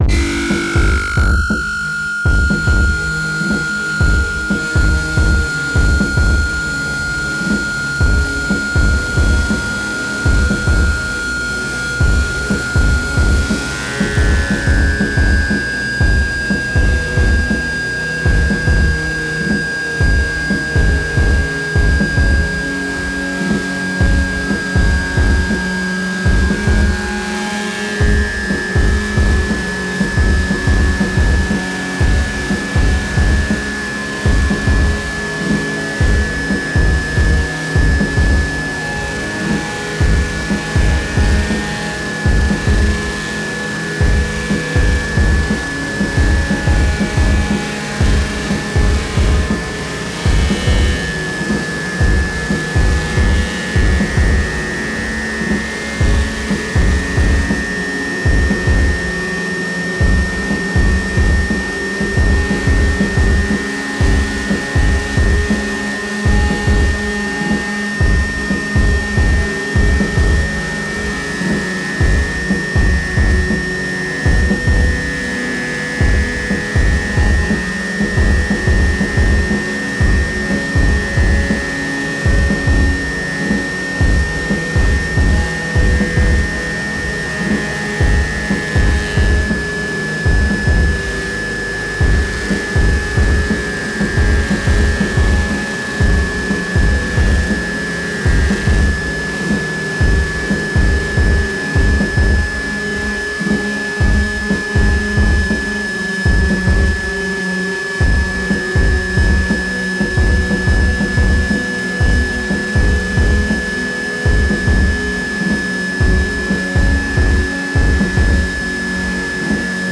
大地を轟かす太く重い狼の”鼓動”。
天と地を結ぶ霊獣の為の、美しく力強い祝祭曲。
たいへんデリケートで複雑なパンニングと、スペクトル分布を特徴とするアルバムです。
現代音楽、先端的テクノ、実験音楽をお好きな方々にはもちろん、ギター愛好家の方々にもお薦めのアルバムです。